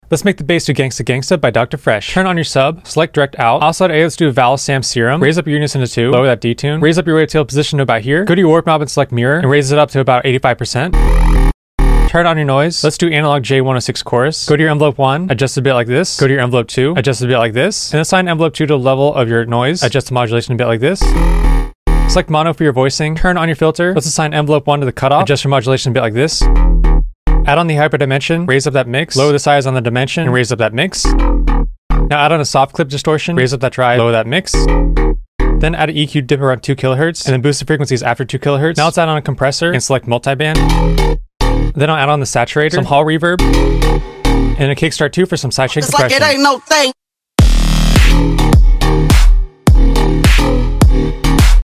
tutorial g-house bass house